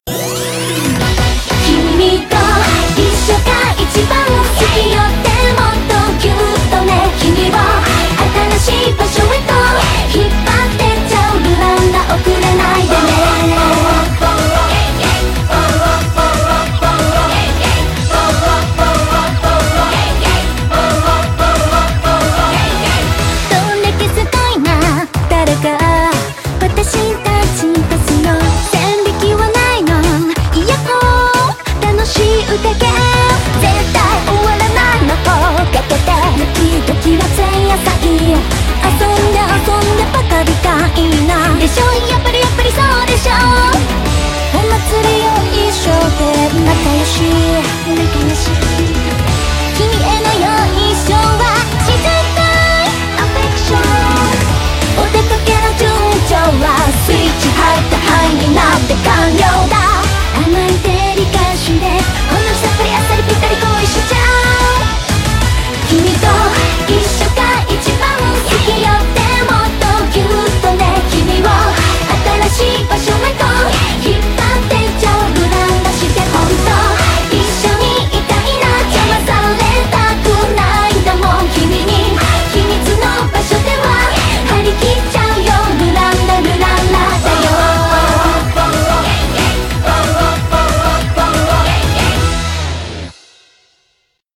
BPM92-184
Audio QualityCut From Video